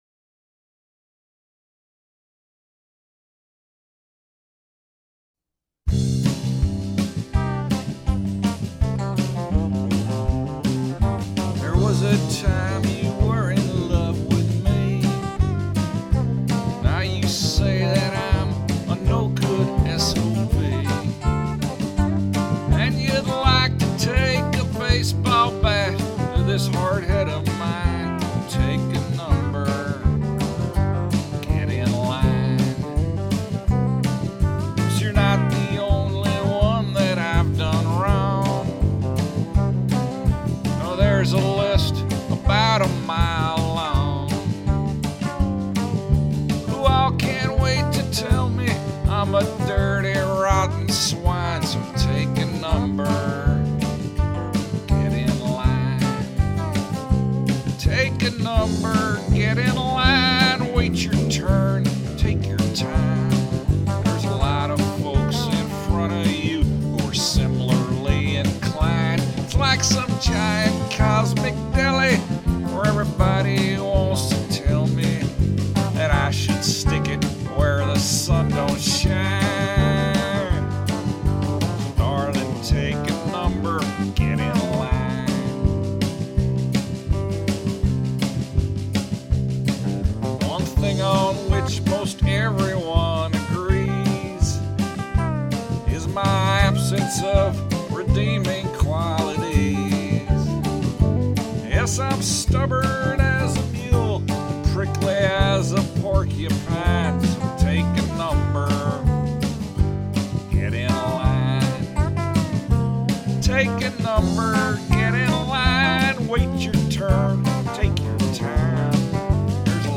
a demo recording